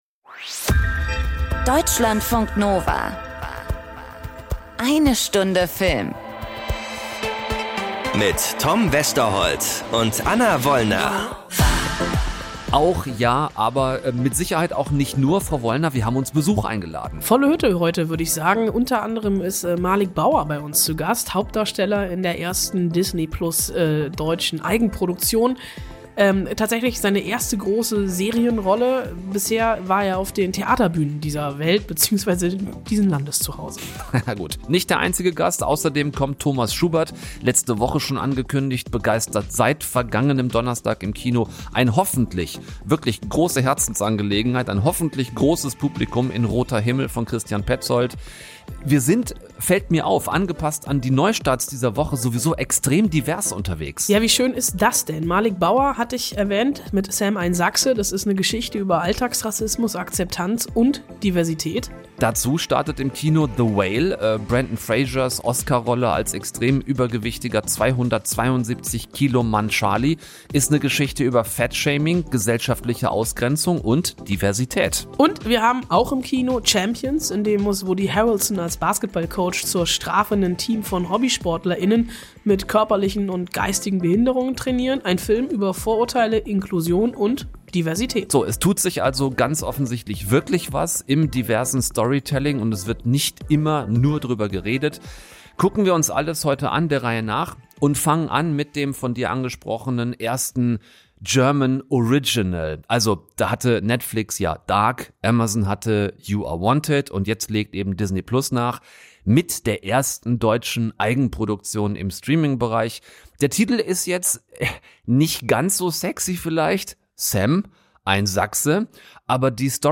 Im Gespräch erzählt er von Alltagsrassismen und Akzeptanz....